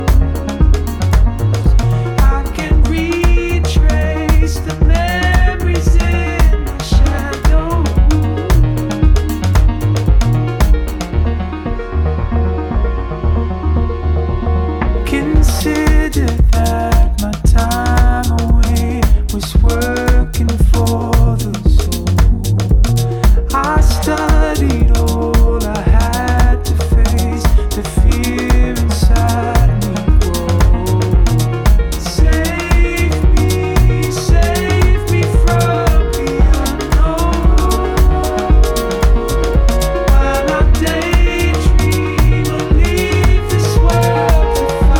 die einige der härtesten und tanzbarsten Grooves enthalten